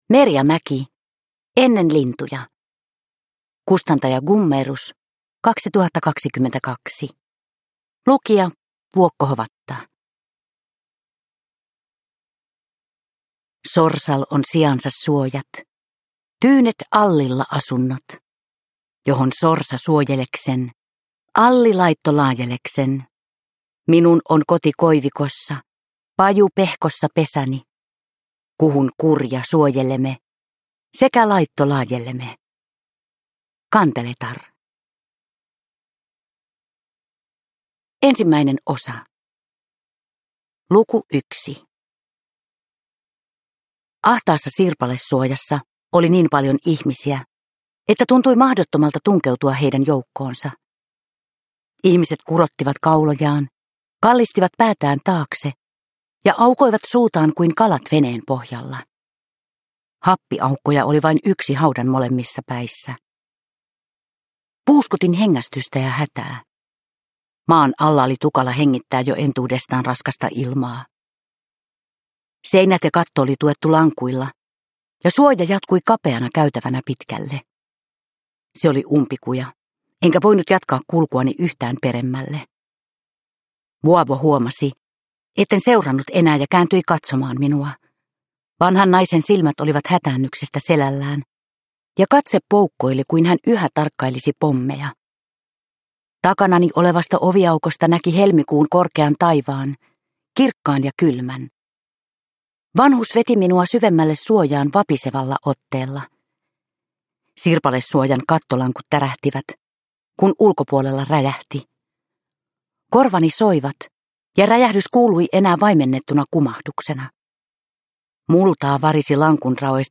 Ennen lintuja – Ljudbok – Laddas ner
Uppläsare: Vuokko Hovatta